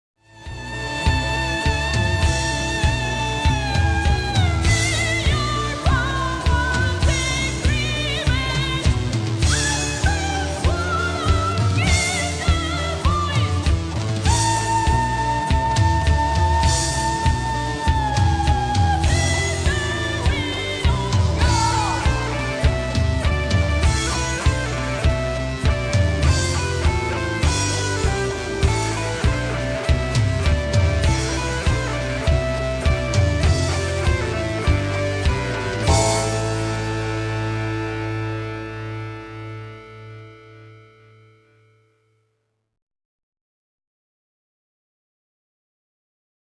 vocals, all guitars, bass guitar, 22 strings harp.
piano, keyboards, bass guitar, flute and all recorders.
drums